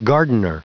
Prononciation du mot gardener en anglais (fichier audio)